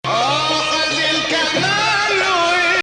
This performance actually uses the Zeffa rhythm typical in weddings.
Maqam Bayati
Performer: Mohamed Kheyri
Bayati 8
melody variant, using bayati on the octave
Bayati Ah Ya Hilu 21 Bayati Octave.mp3